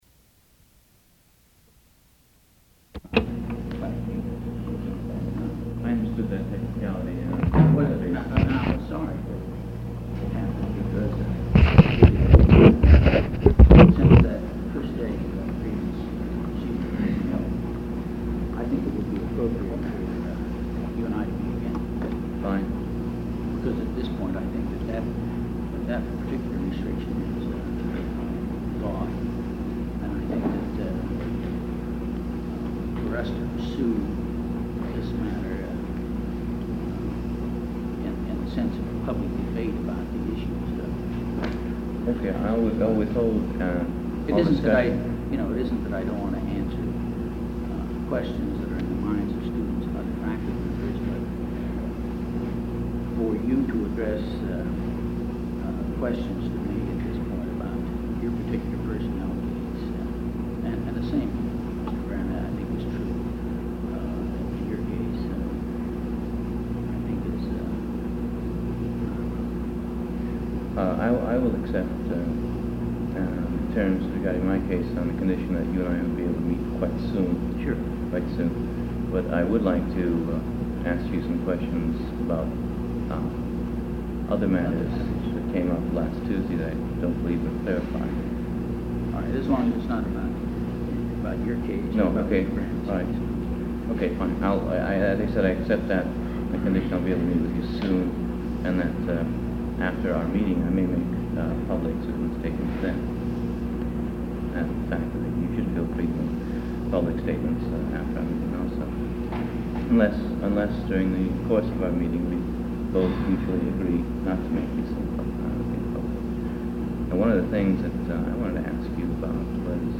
Meeting with students and faculty regarding tenure and non-retention, 2nd session